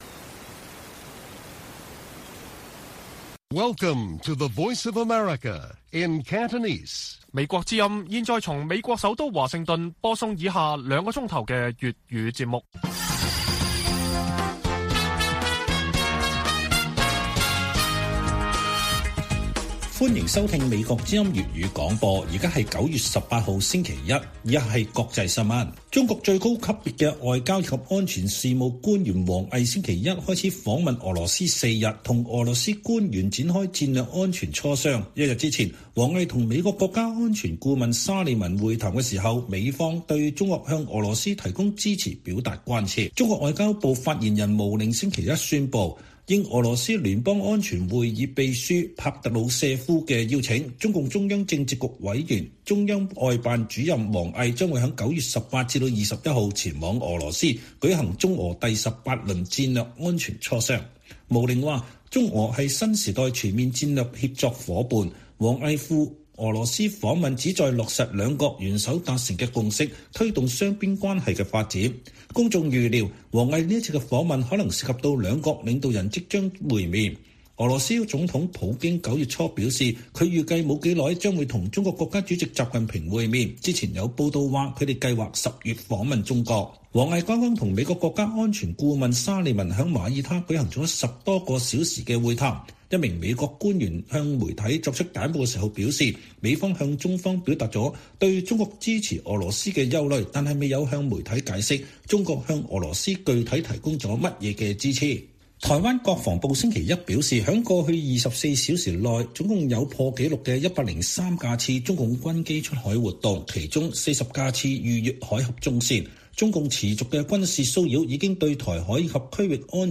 粵語新聞 晚上9-10點: 美國關切王毅訪問俄羅斯